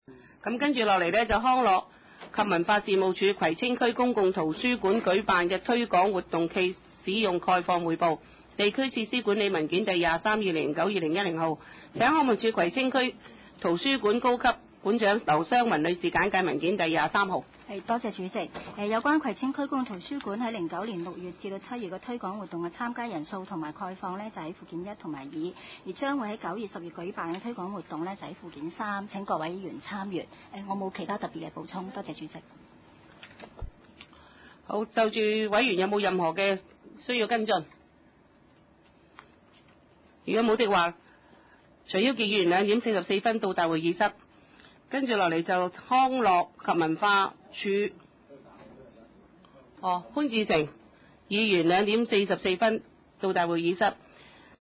葵青民政事務處會議室